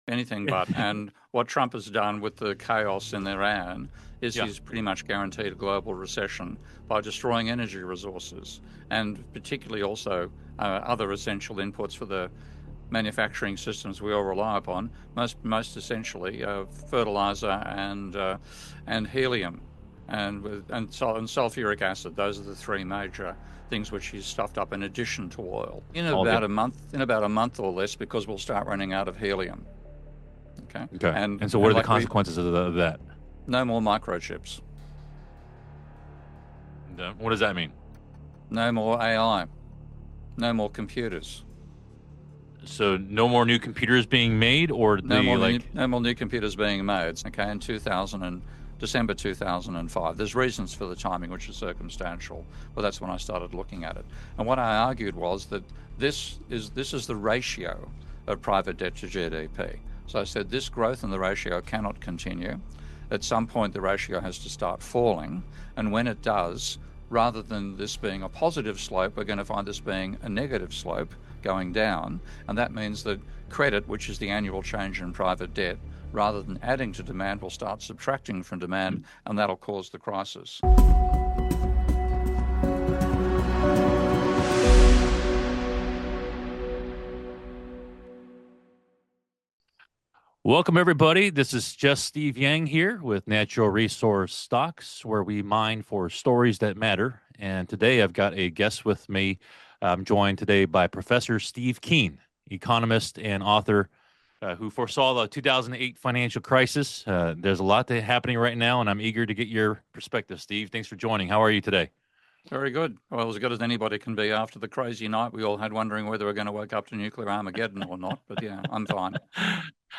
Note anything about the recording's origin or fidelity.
at 2024 Rule Symposium